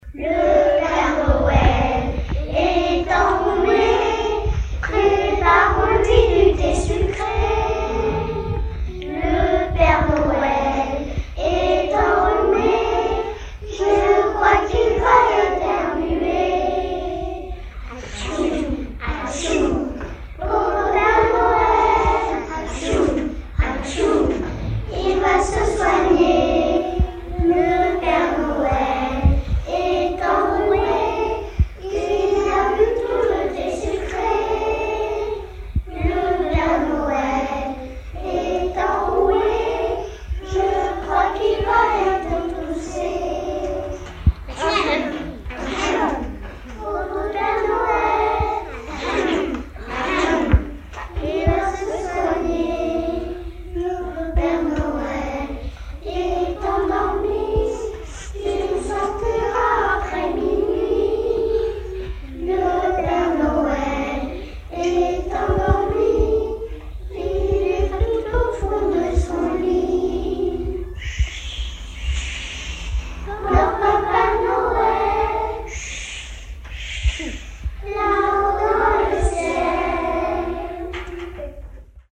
Les_eleves_chantent_Le_pere_noel_est_enrhume.mp3